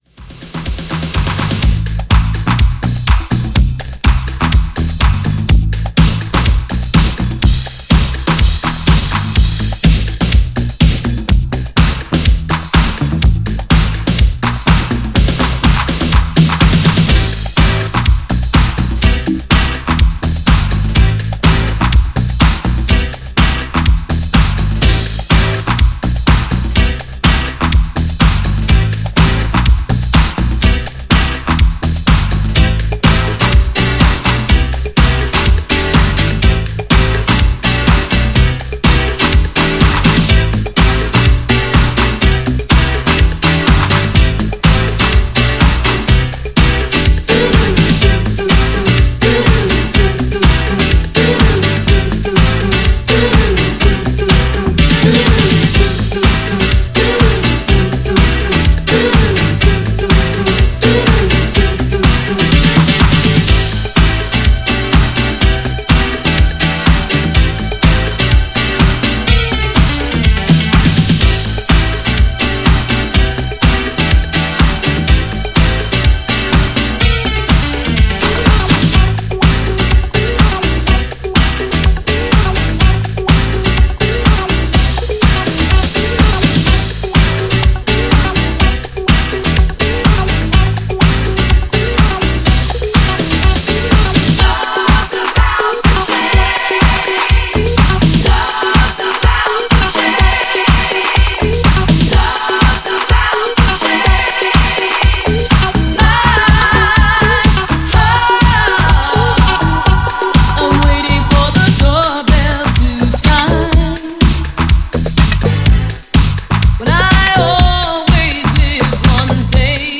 GENRE Dance Classic
BPM 121〜125BPM